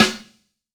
SNARE 112.wav